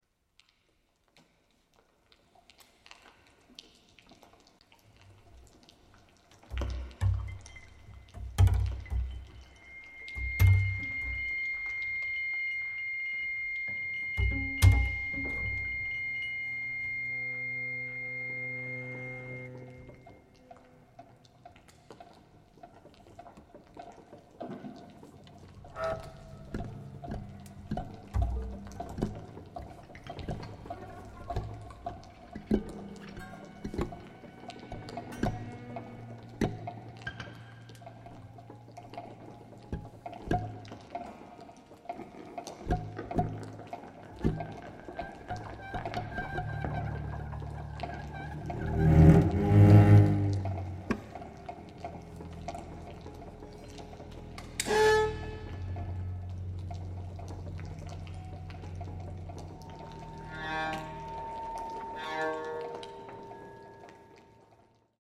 recorded October 1, 2024 in Jordan Hall, Boston